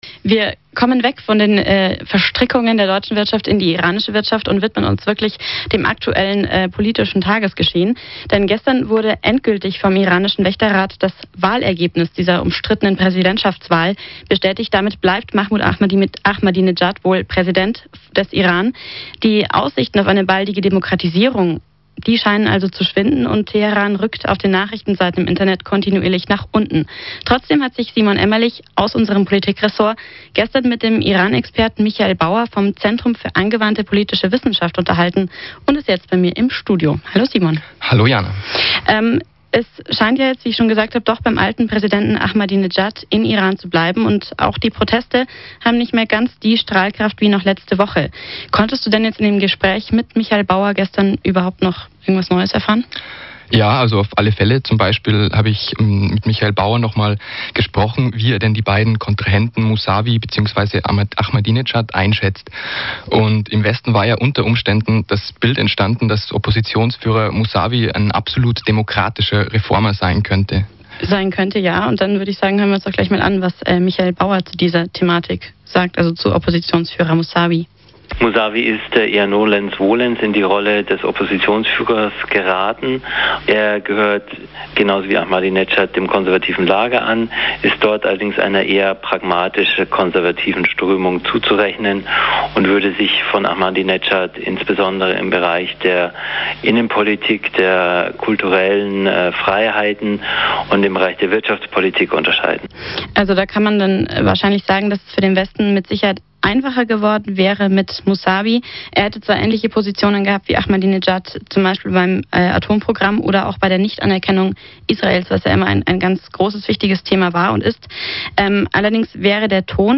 Im Gespräch mit